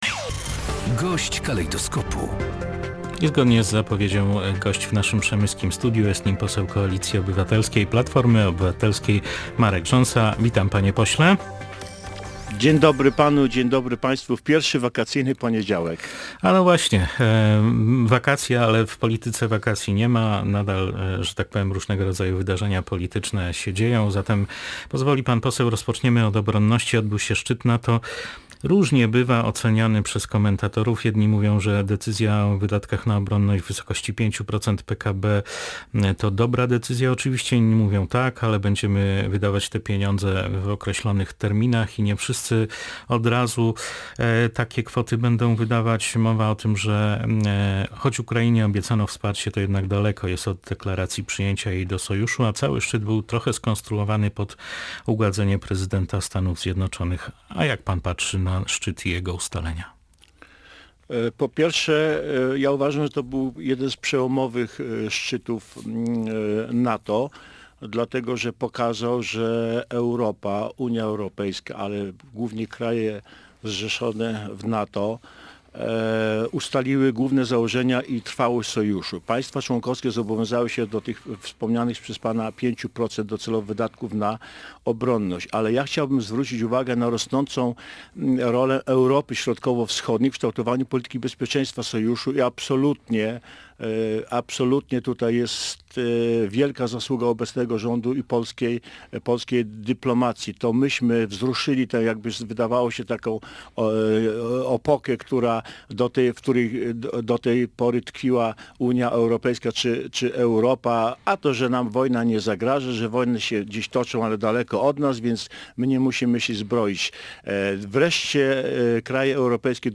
Gość dnia • Wielki sukces – takimi słowami Marek Rząsa, poseł KO-PO podsumował polską prezydencję w Unii Europejskiej.
Jak powiedział polityk na antenie Polskiego Radia Rzeszów, słowo „Solidarność” udało się zaszczepić w sercach wszystkich Europejczyków.